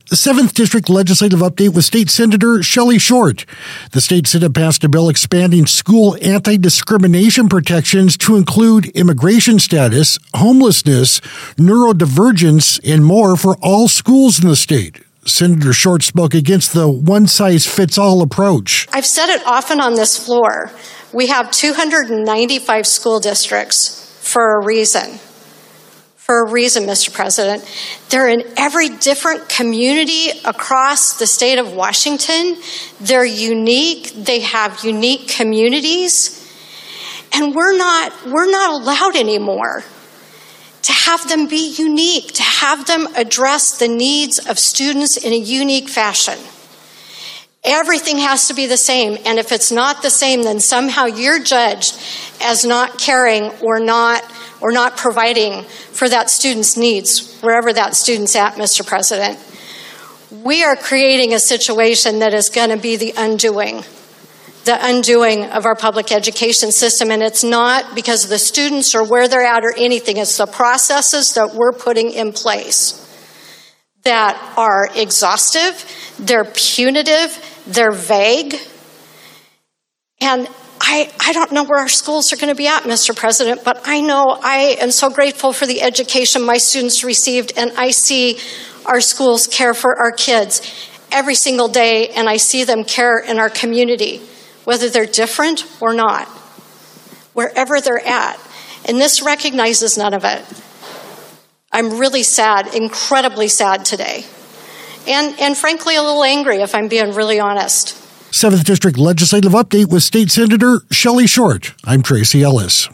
AUDIO: 7th District Legislative Update with State Senator Shelly Short - Senate Republican Caucus